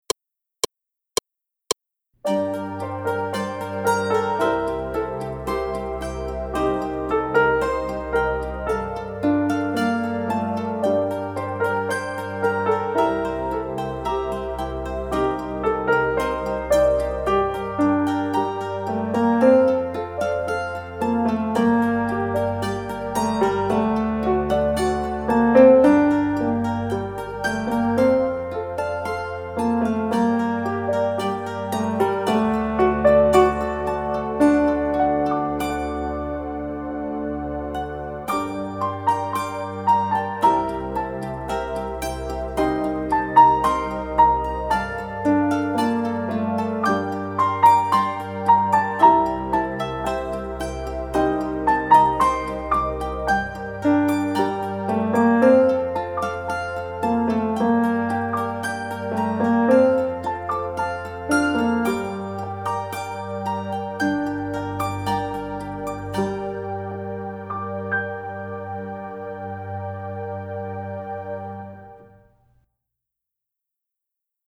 합주